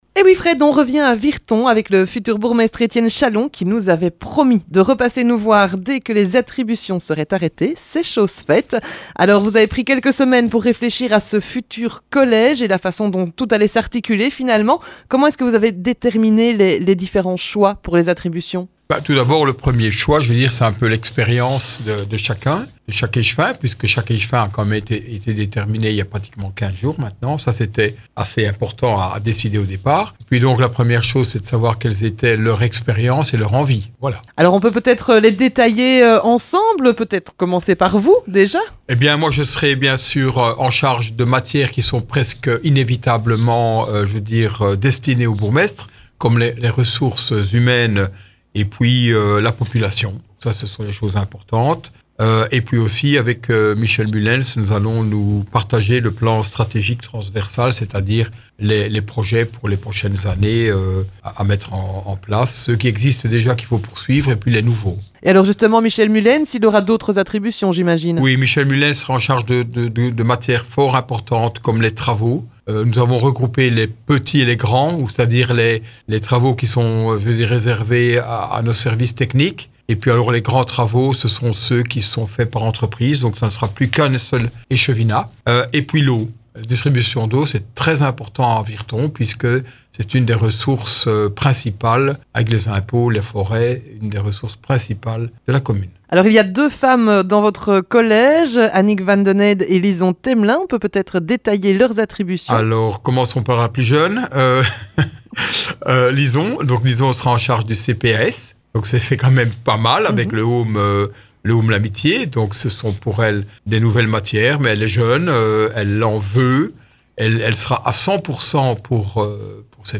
Le futur bourgmestre de Virton, Etienne Chalon est passé dans nos studios pour détailler son collège communal et les attributions de chacun.